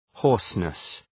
Προφορά
{‘hɔ:rsnıs}